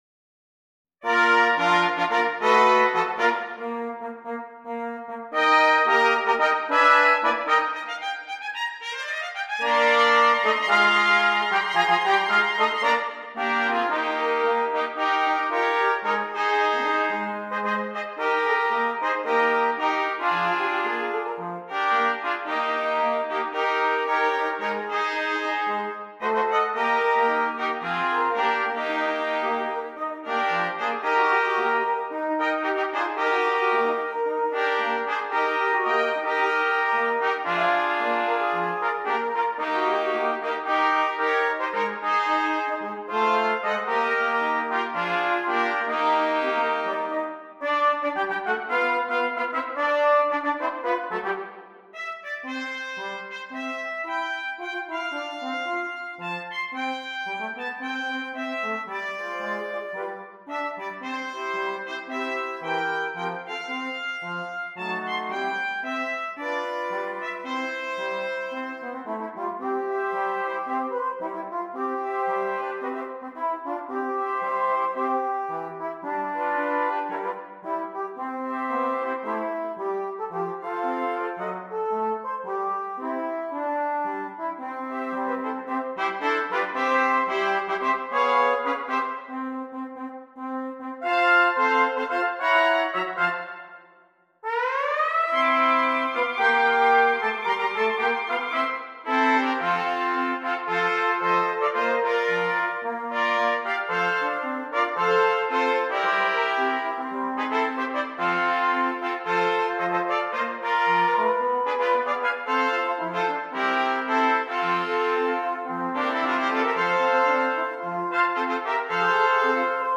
5 Trumpets
Traditional